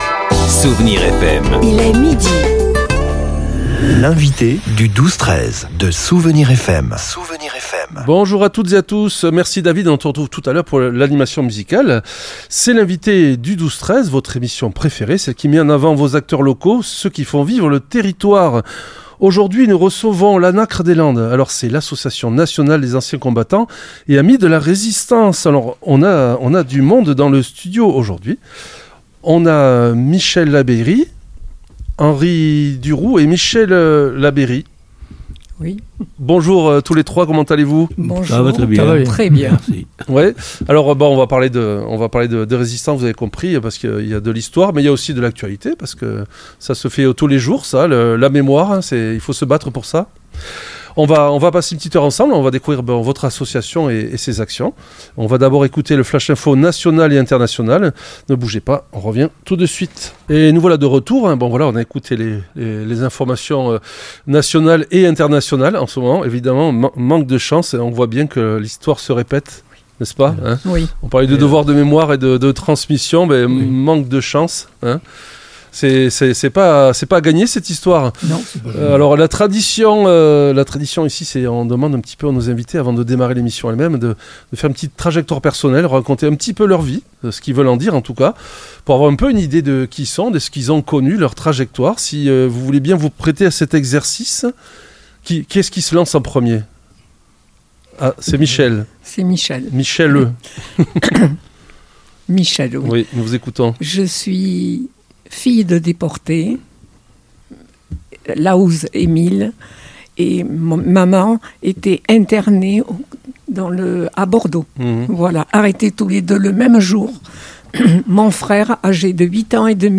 L'entretien a mis en lumière des figures locales marquantes comme Camille Bouvet, héros de la zone occupée exécuté au Mont-Valérien, mais aussi des lieux de recueillement symboliques comme le mémorial de Téthieu, rendant hommage aux 350 martyrs landais.